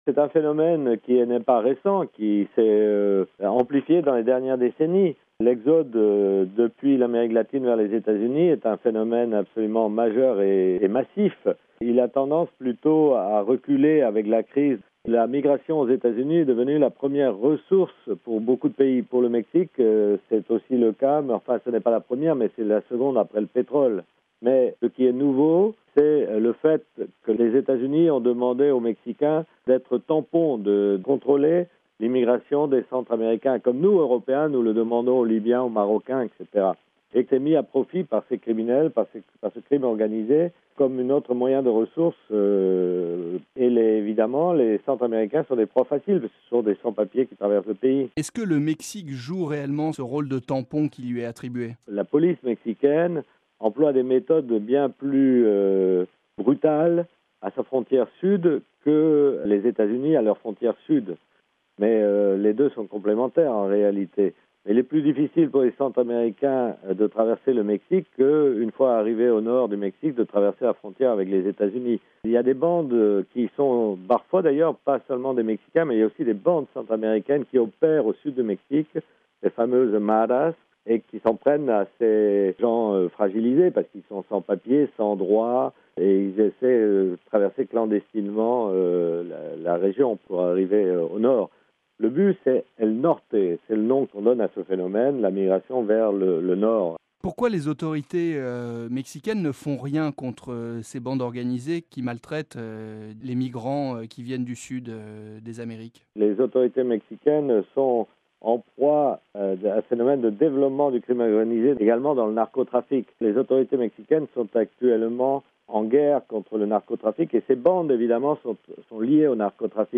Des propos recueillis